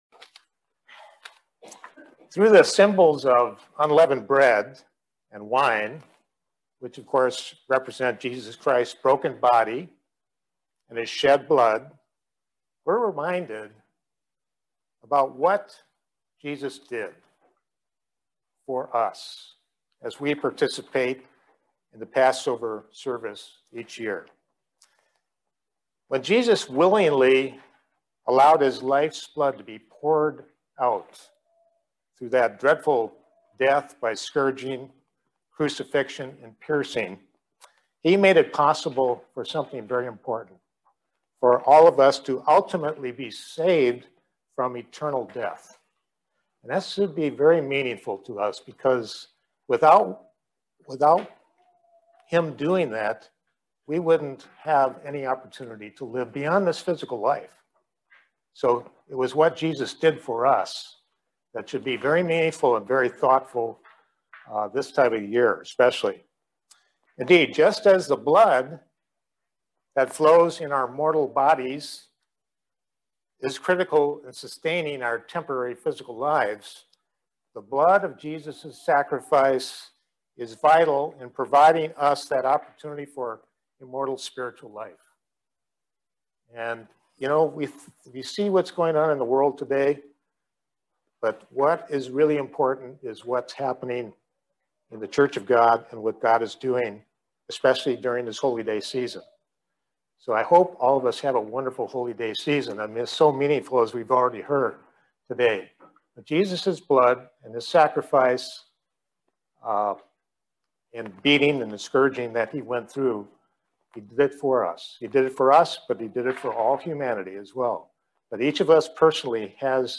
Join us for this excellent video Sermon on the subject of what our Lord and Savior Jesus Christ did for us. Very good reminder leading up to Passover.